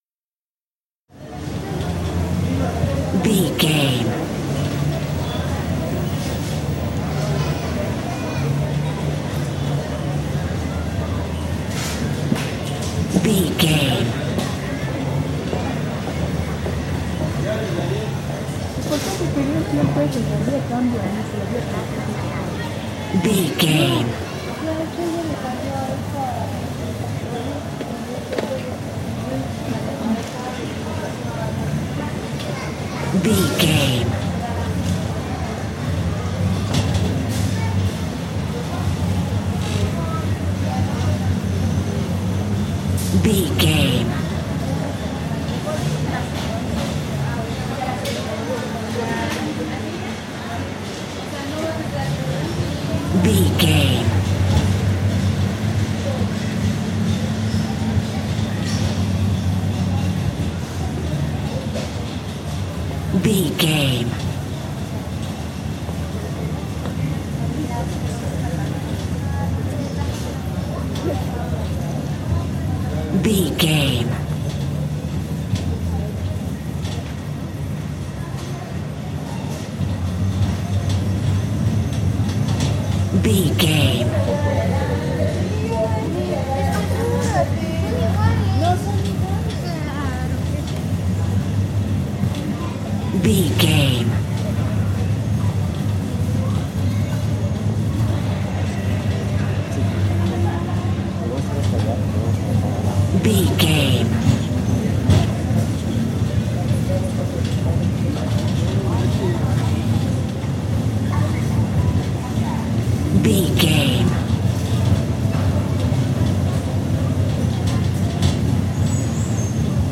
Supermarket ambience
Sound Effects
urban
backgrounds